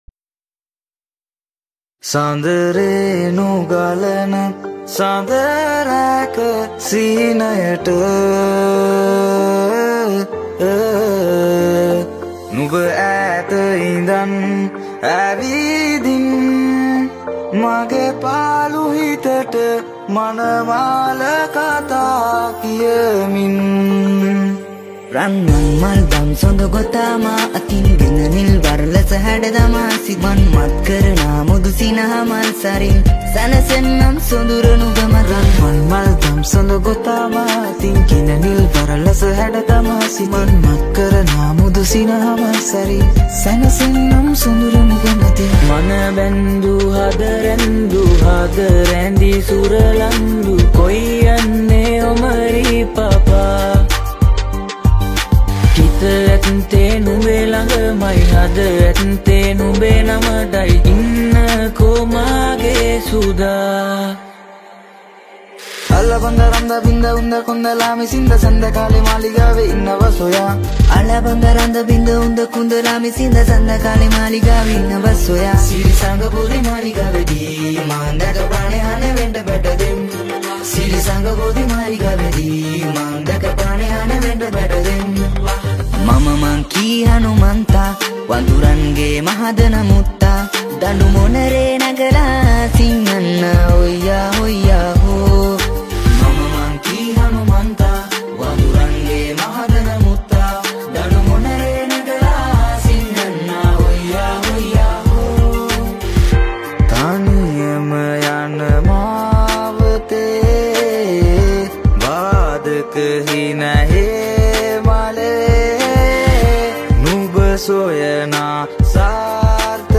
High quality Sri Lankan remix MP3 (4.5).